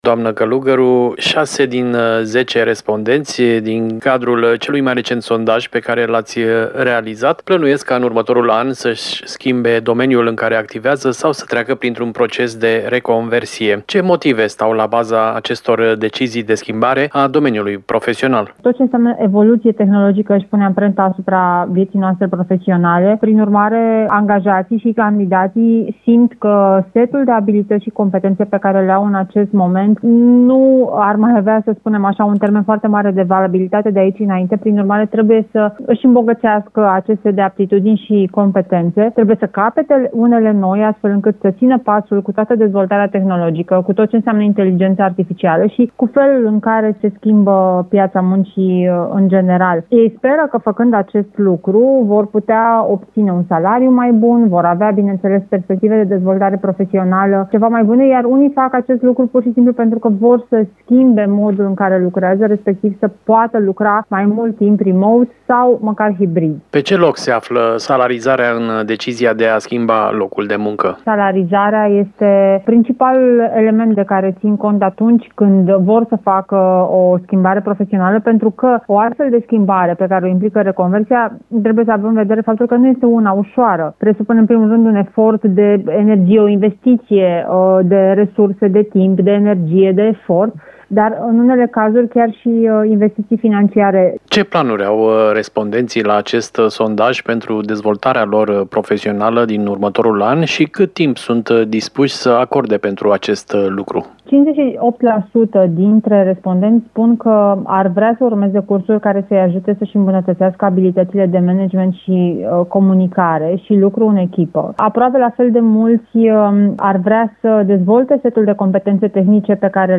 a discutat subiectul cu expertul în comunicare